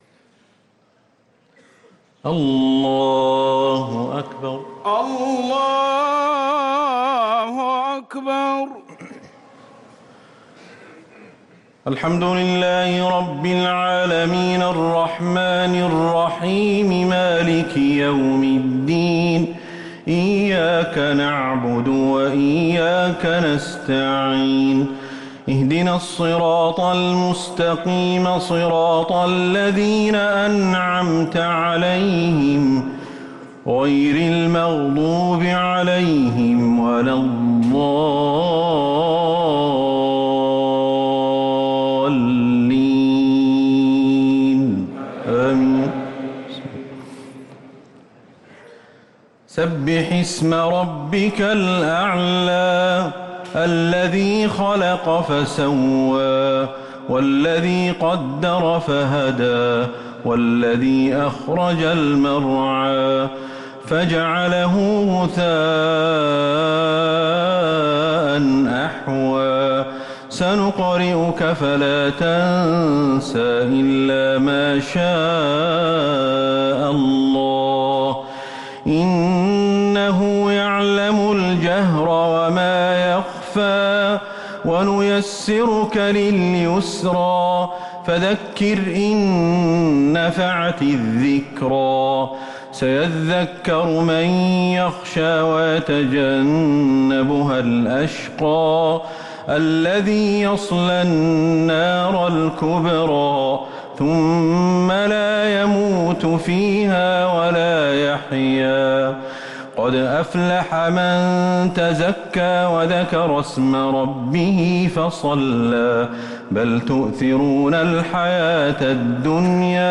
صلاة الشفع والوتر مع دعاء القنوت ليلة 24 رمضان 1444هـ > رمضان 1444هـ > التراويح